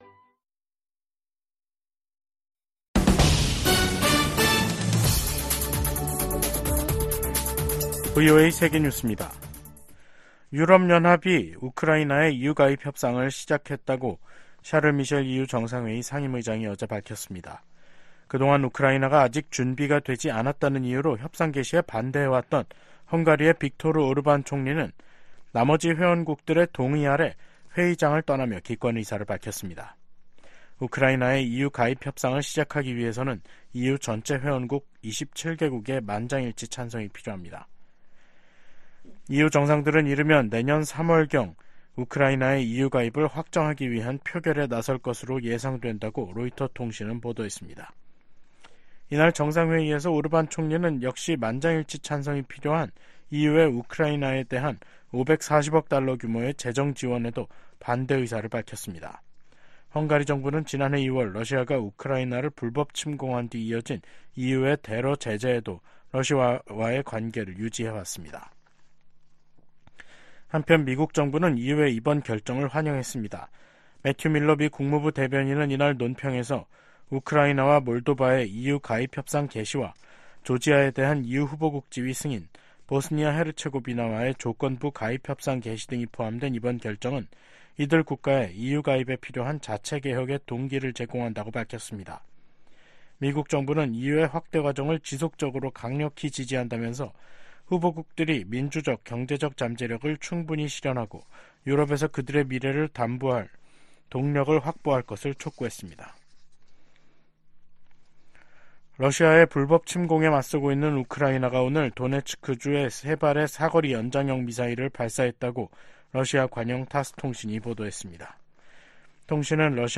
VOA 한국어 간판 뉴스 프로그램 '뉴스 투데이', 2023년 12월 15일 2부 방송입니다. 내년도 회계연도 미국 국방 정책의 방향과 예산을 설정한 국방수권법안이 의회를 통과했습니다. 미국 정부가 한반도 완전한 비핵화 목표에 변함 없다고 확인했습니다. 영국 상원이 북한의 불법적인 무기 개발과 북러 간 무기 거래, 심각한 인권 문제 등을 제기하며 정부의 대응을 촉구했습니다.